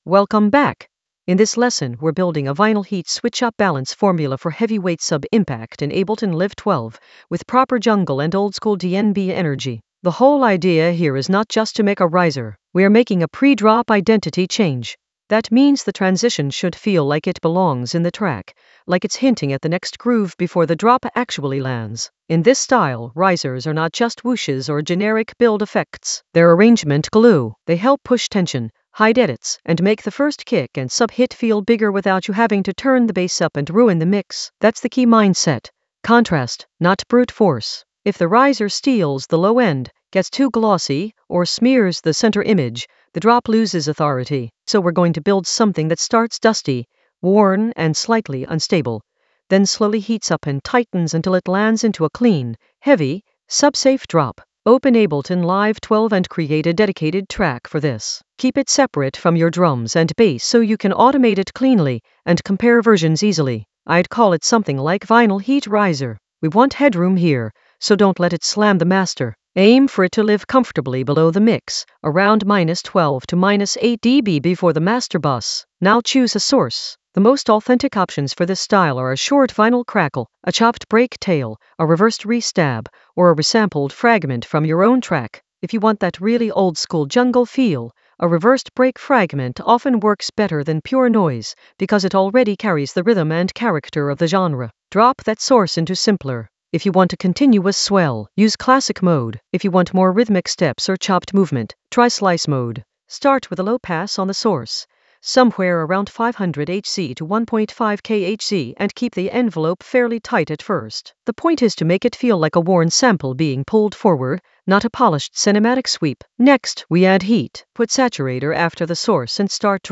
An AI-generated advanced Ableton lesson focused on Vinyl Heat switch-up balance formula for heavyweight sub impact in Ableton Live 12 for jungle oldskool DnB vibes in the Risers area of drum and bass production.
Narrated lesson audio
The voice track includes the tutorial plus extra teacher commentary.